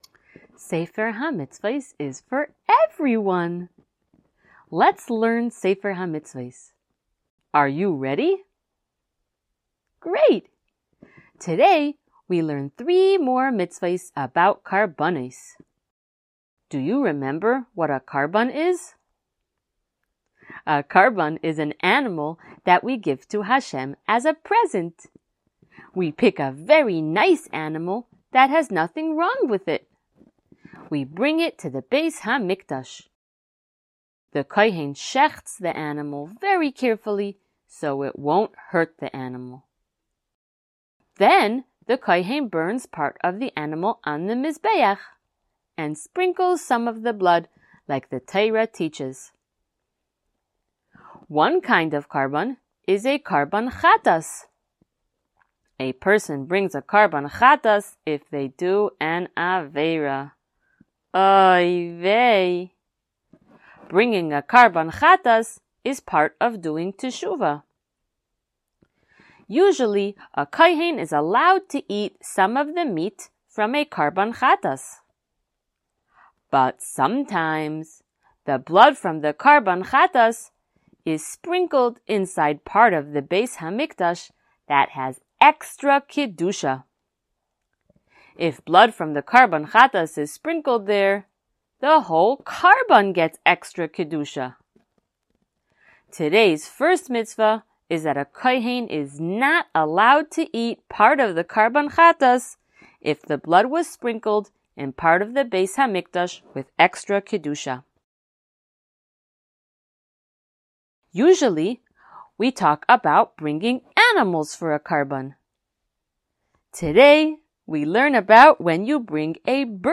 SmallChildren_Shiur160.mp3